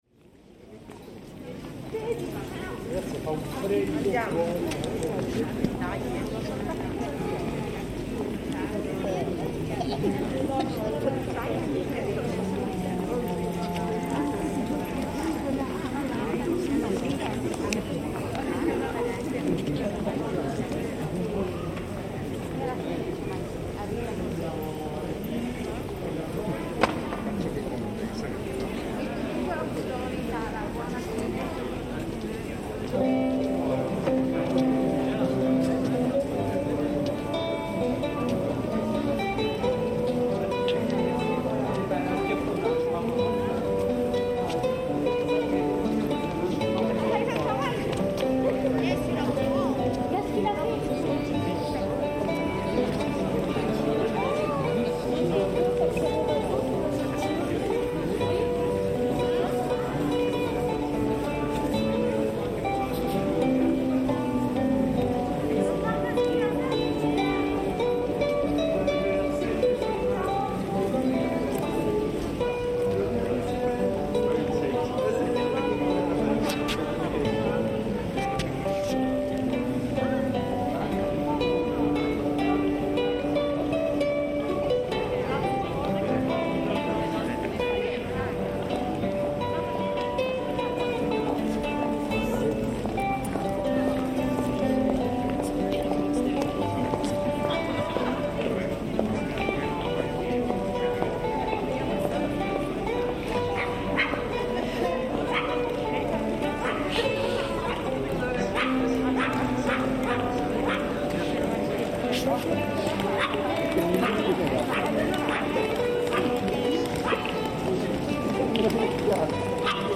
A busker plays